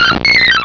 pokeemerald / sound / direct_sound_samples / cries / bellossom.aif